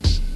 Kick (Foreword).wav